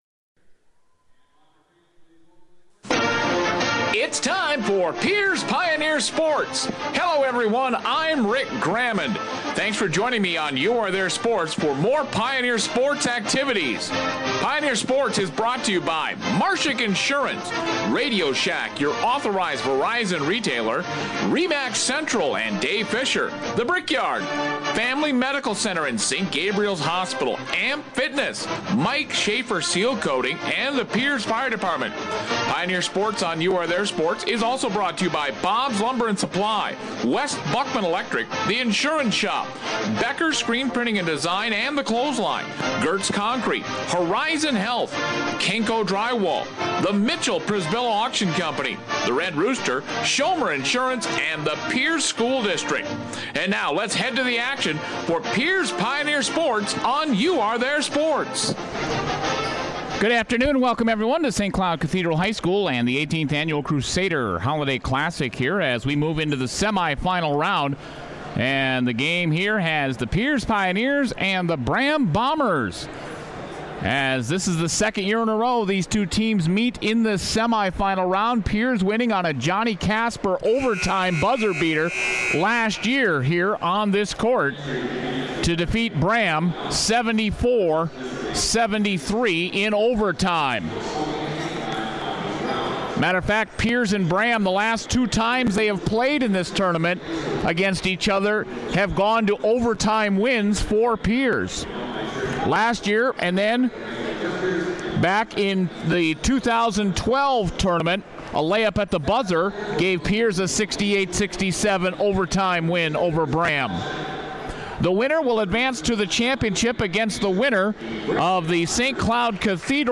12/29/15 Pierz Pioneers vs Braham Bombers Boys Basketball Crusader Classic Semifinals